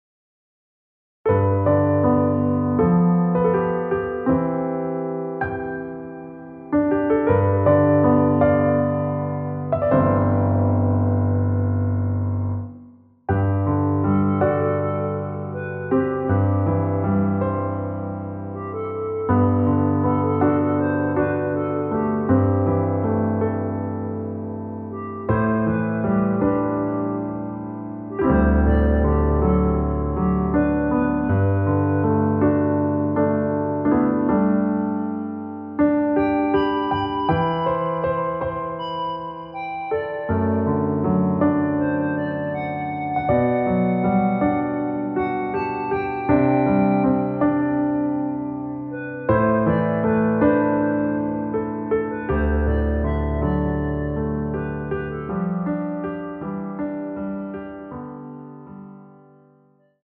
원키에서(+4)올린 멜로디 포함된 MR입니다.
앞부분30초, 뒷부분30초씩 편집해서 올려 드리고 있습니다.
중간에 음이 끈어지고 다시 나오는 이유는